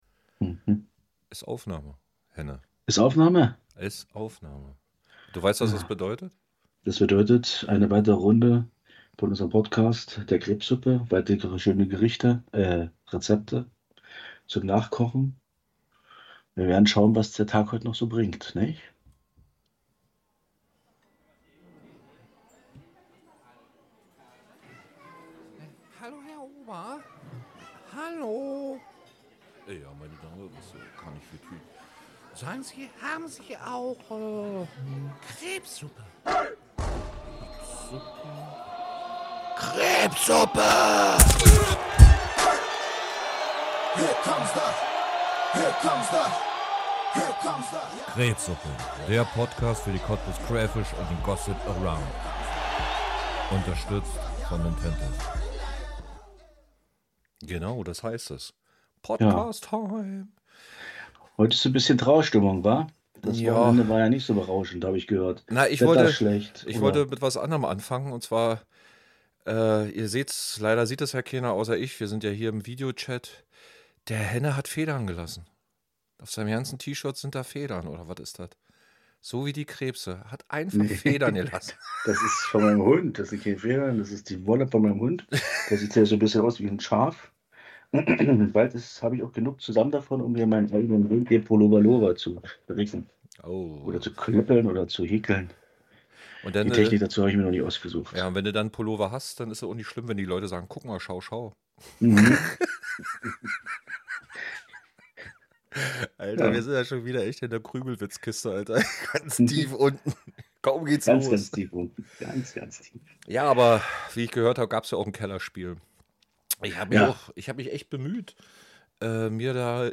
als Gast haben wir einen ehemaligen Quarterback bei uns am Rohr. Ansonsten viel Gelabere und hoffentlich ist was lustiges dabei.